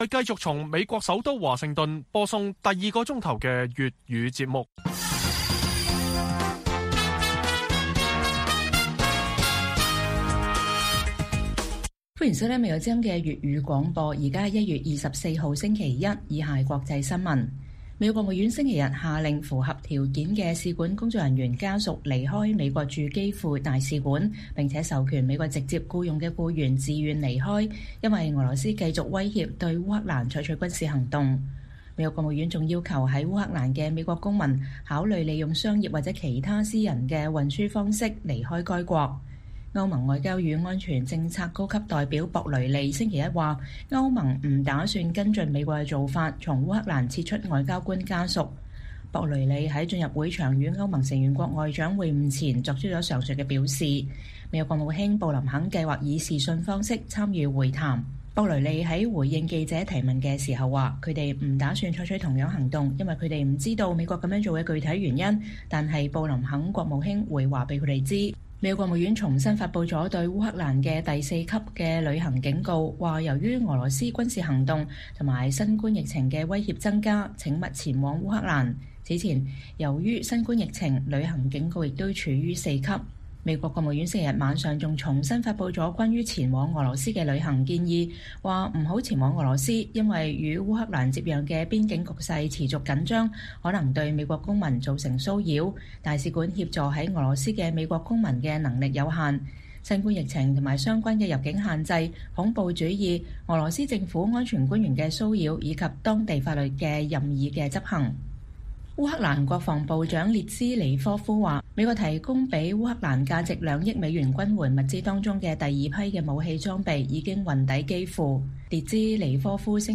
粵語新聞 晚上10-11點: 香港新冠病毒社區爆發持續擴大 有居民質疑禁足5日成效不大